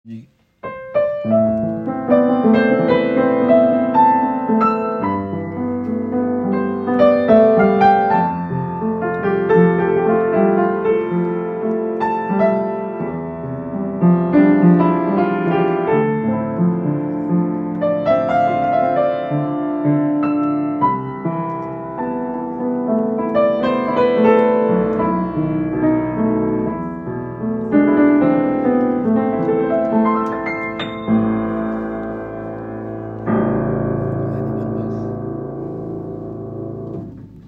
Piano IBACH
piano en acajou satiné Hauteur : 113cm Année 1973 3 pédales dont sourdine, 2 lampes Belle sonorité, clavier de 88 notes Prix : 5000€